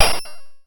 bleep 2
beep bleep button digital ding effect nord sfx sound effect free sound royalty free Sound Effects